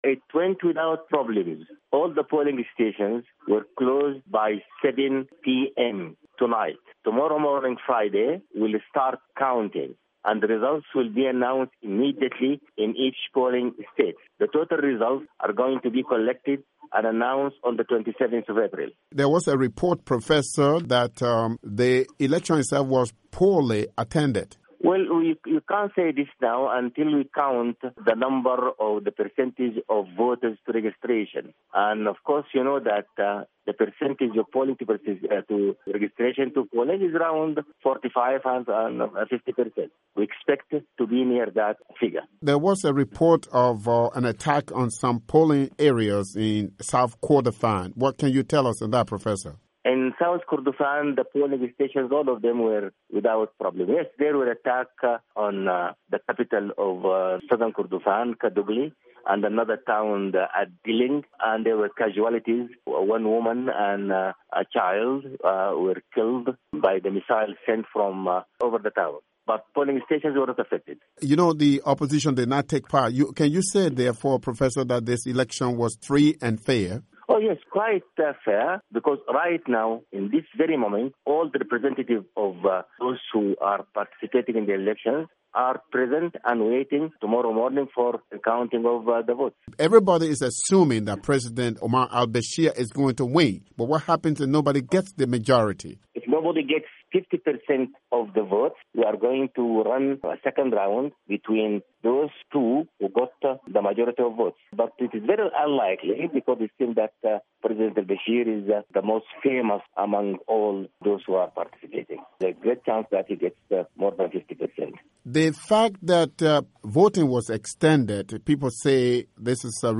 interview with Mukhtar Al-Assam